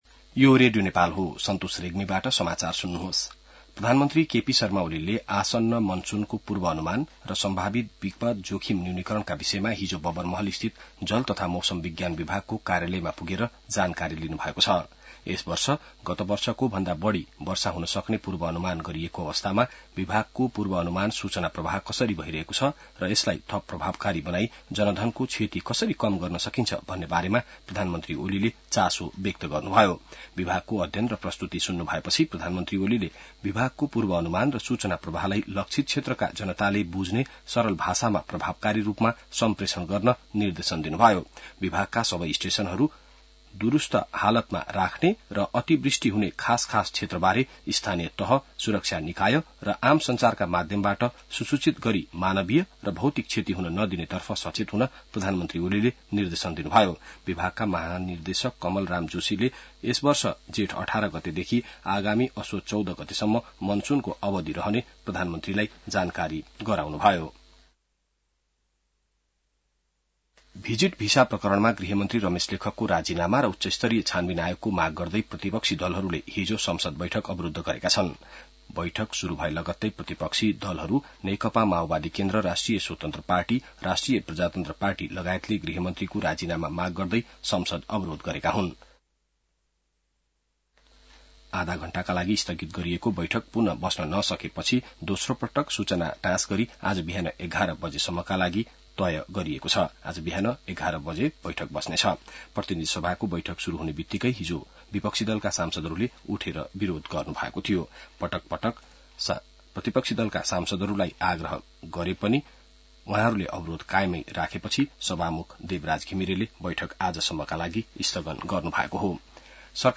बिहान ६ बजेको नेपाली समाचार : १४ जेठ , २०८२